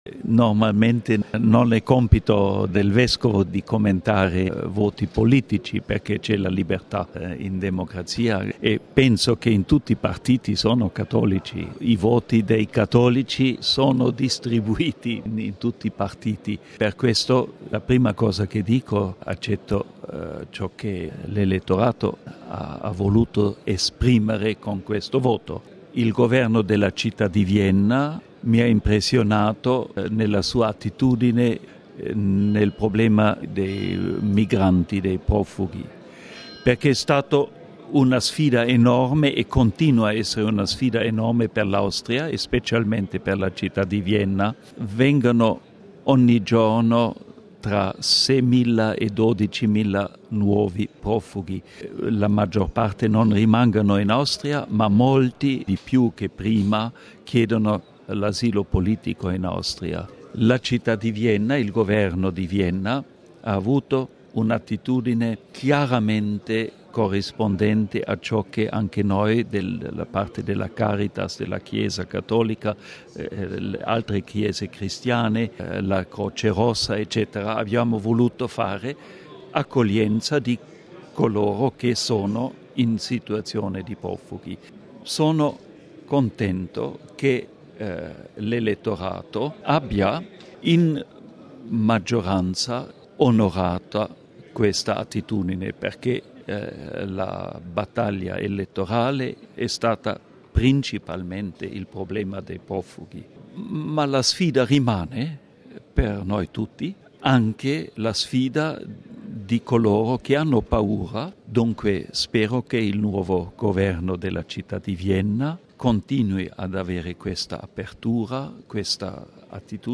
Bollettino Radiogiornale del 12/10/2015